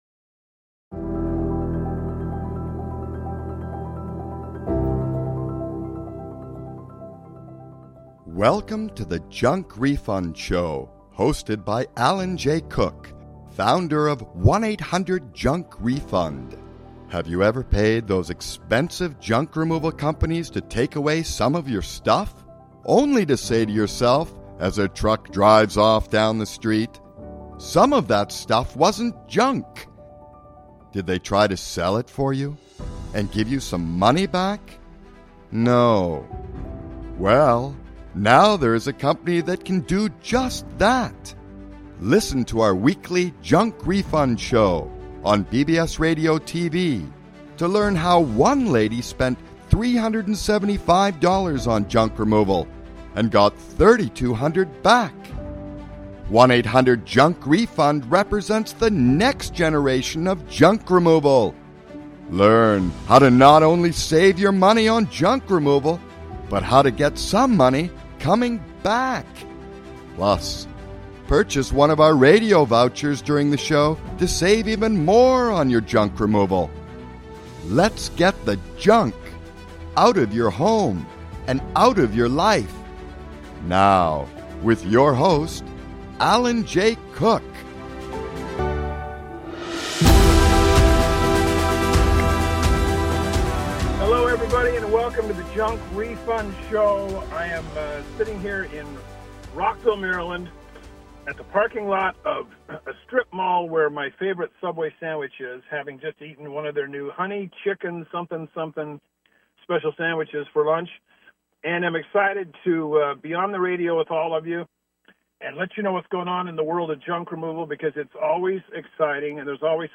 Live from Rockville, Maryland at the Subway parking lot.